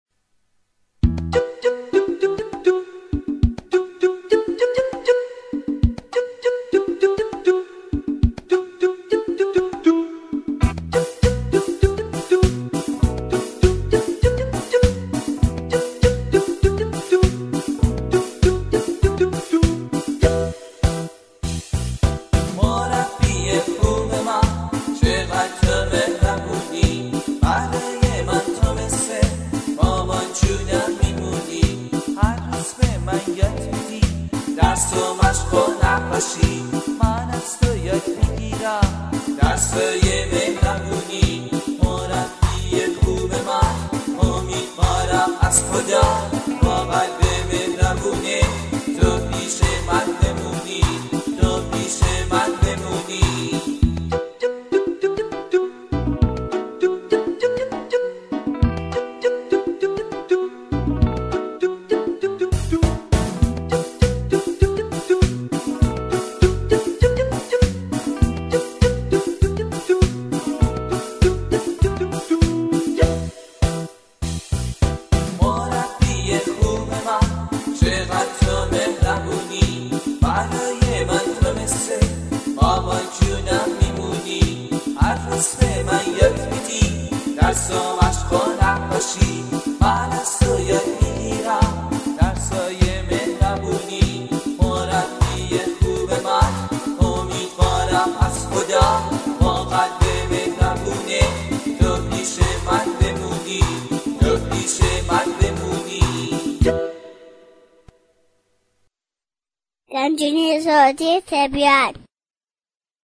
دانلود آهنگ شاد کودکانه در مورد معلم